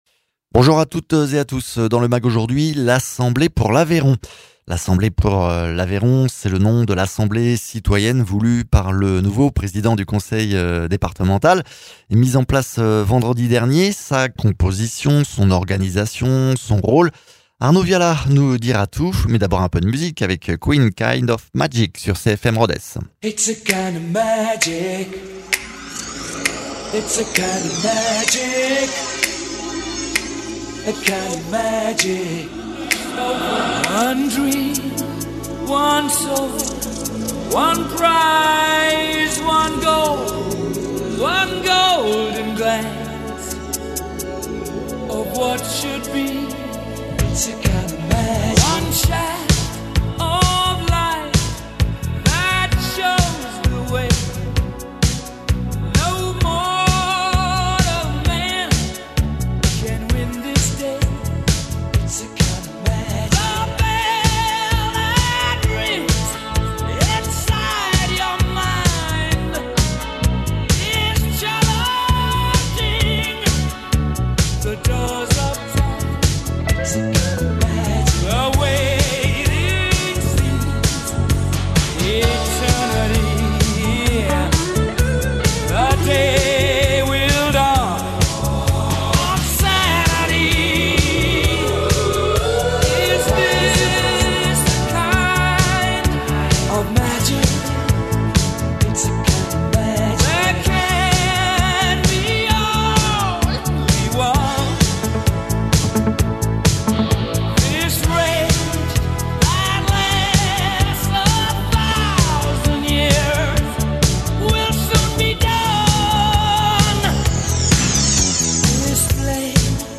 Invité(s) : Arnaud Viala, Président du conseil départemental de l’Aveyron